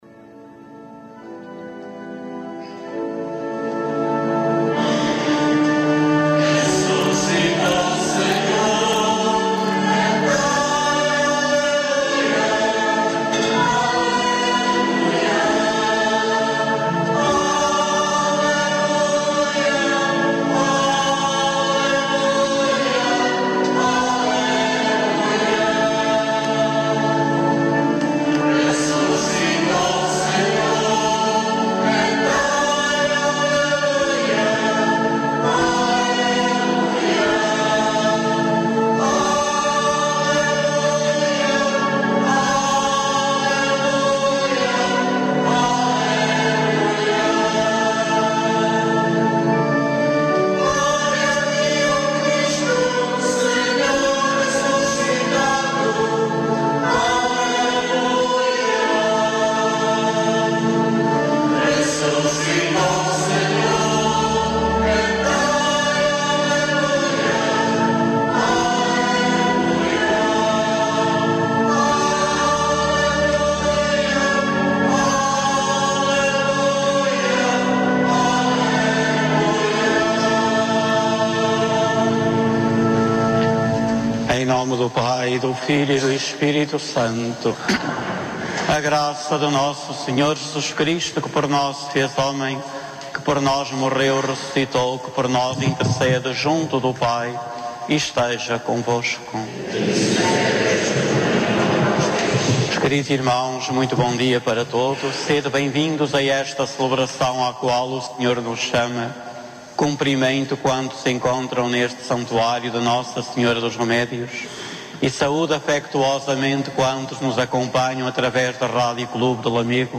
A Rádio Clube de Lamego transmite todos os Domingos a Eucaristia, em direto, desde o Santuário de Nossa Senhora dos Remédios em Lamego a partir das 10 horas.
com a participação do Coro do Santuário de Nossa Senhora dos Remédios.